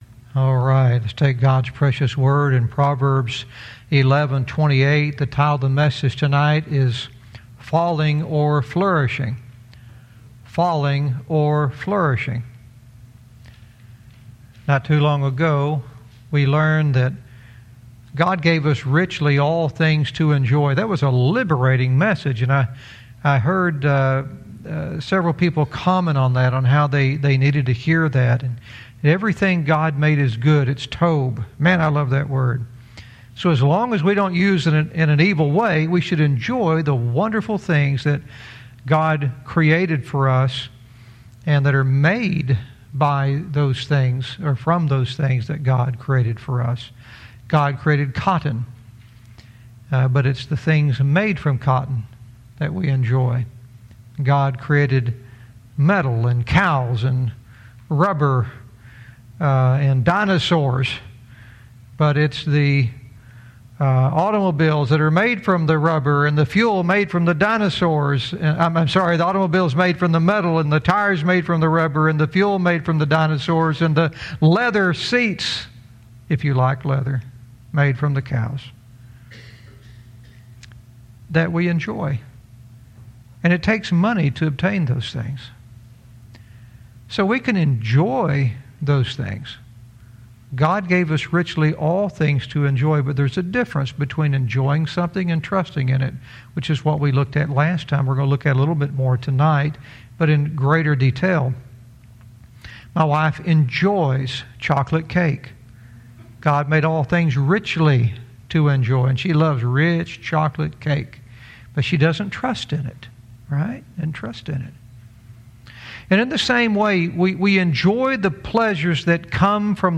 Verse by verse teaching - Proverbs 11:28 "Falling or Flourishing"